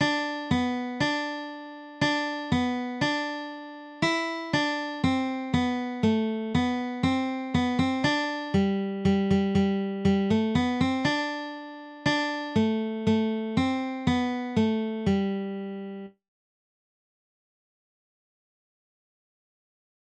Beginner Guitar Solo
Kids Song